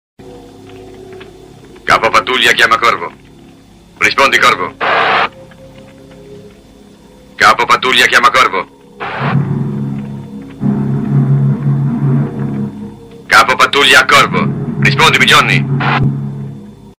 Categoria Effetti Sonori